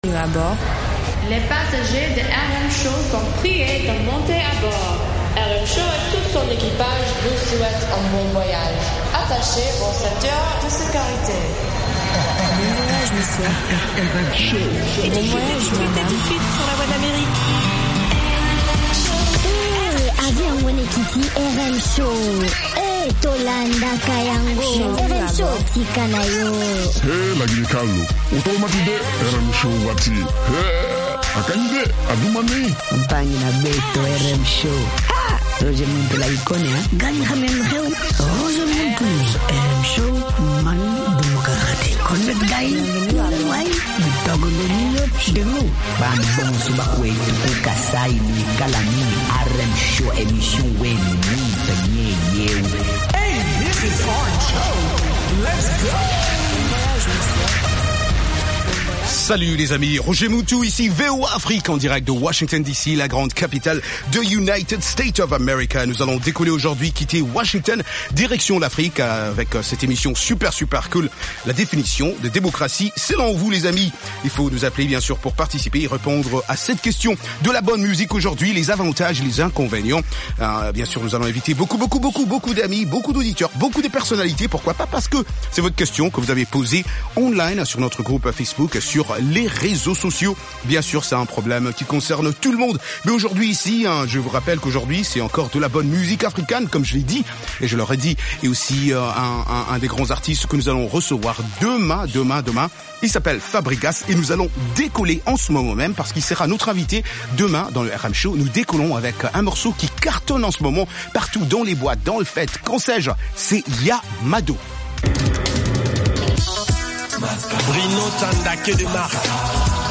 des reportages et interviews sur des événements et spectacles africains aux USA ou en Afrique.